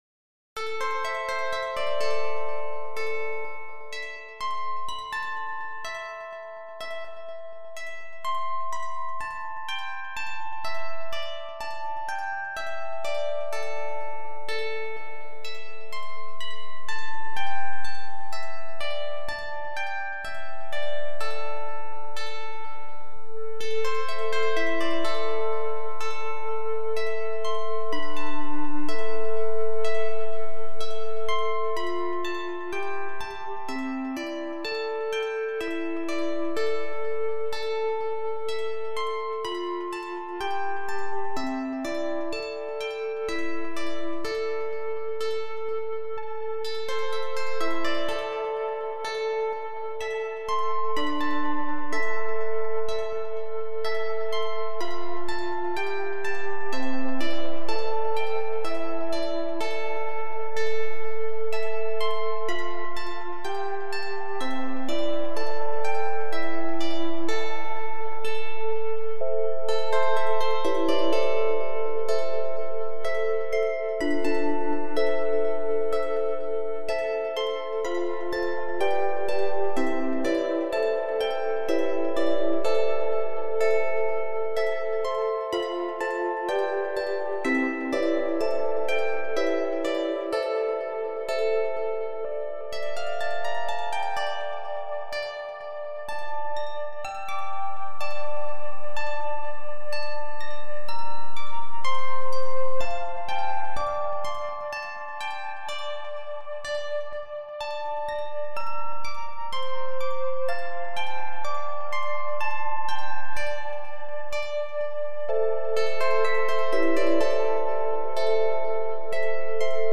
Nie jest to typowa (a jest taka?) muzyka elektroniczna, staram się połączyć tradycyjne środki wyrazu z możliwościami, jakie daje technologia.
CZEREZ POLIE - tradycyjna melodia ukraińska (2,3 Mb)